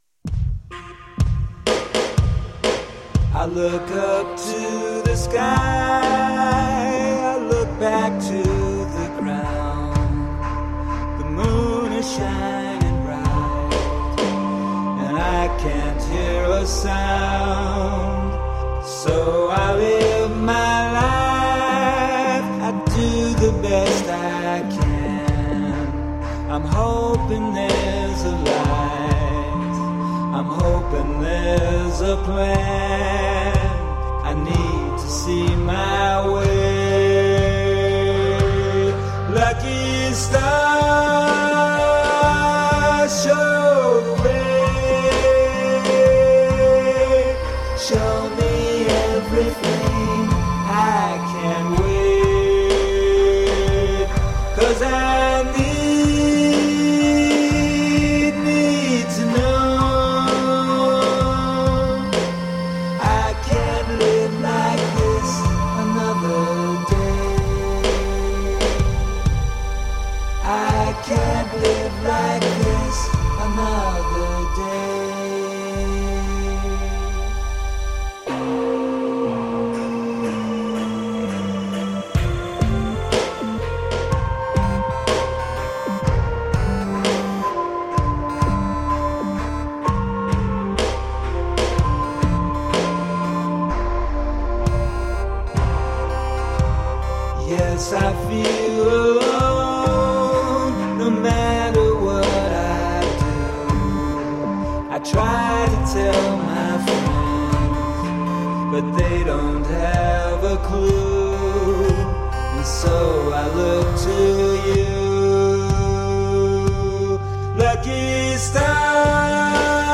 Rock in the style of the 1960's british wave.
Tagged as: Alt Rock, Other, Prog Rock